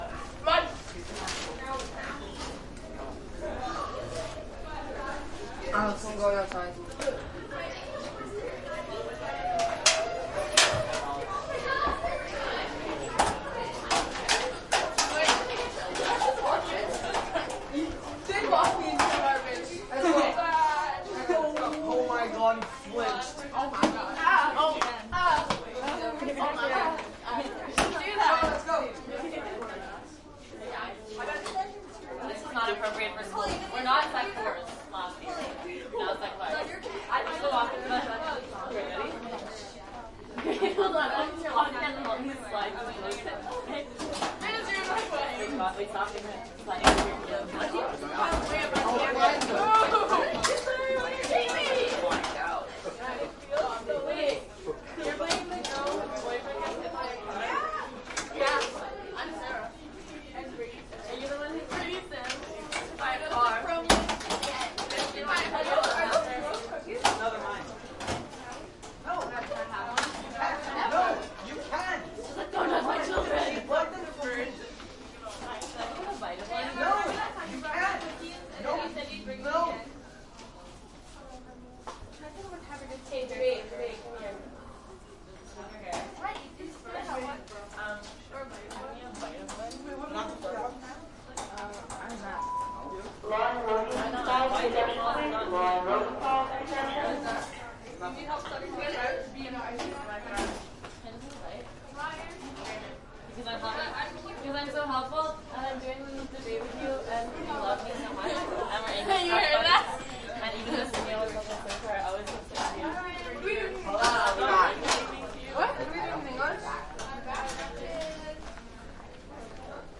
高中" 人群中高中走廊的储物柜中等繁忙
描述：人群int高中走廊储物柜中等忙
标签： 储物柜 INT 人群 学校 走廊
声道立体声